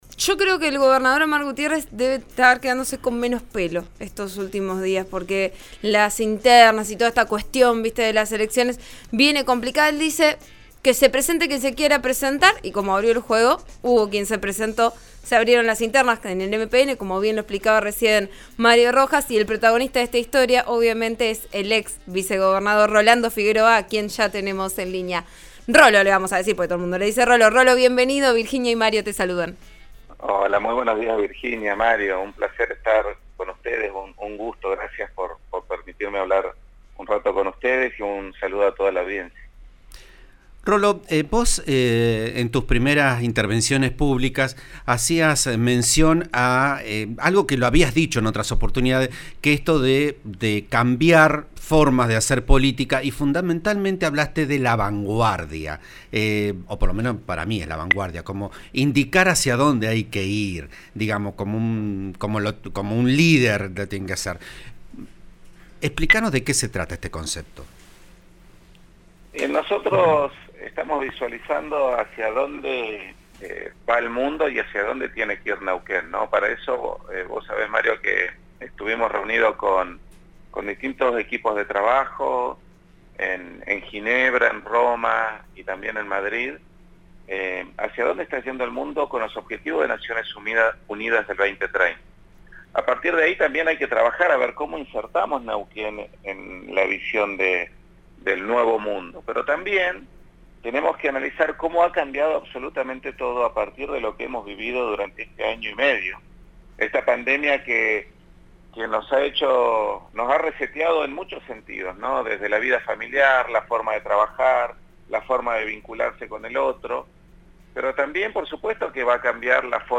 En diálogo con Vos a Diario el programa de RN Radio, el dirigente explicó los motivos por los cuales convocó a la profesional y aseguró que no está pensando si su lista servirá de depositaria o no de un «voto castigo» al oficialismo.